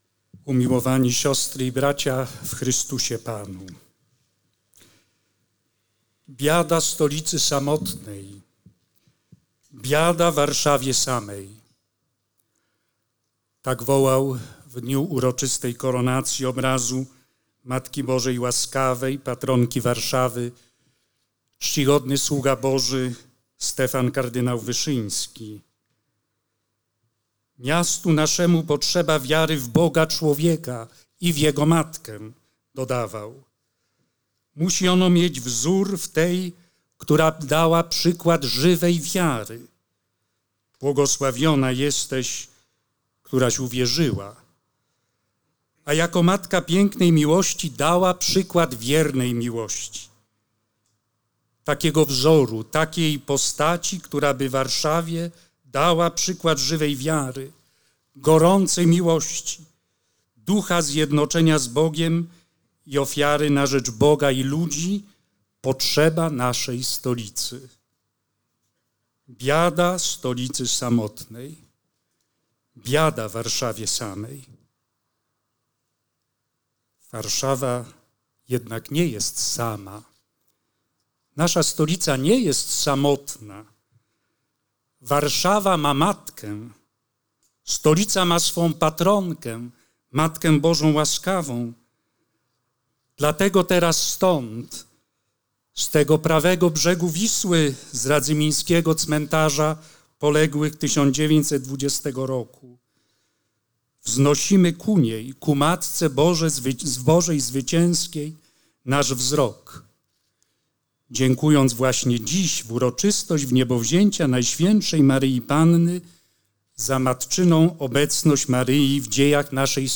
Prymas Polski przewodniczył Eucharystii na Cmentarzu Żołnierzy Polskich 1920 roku w Radzyminie.
homilia.wav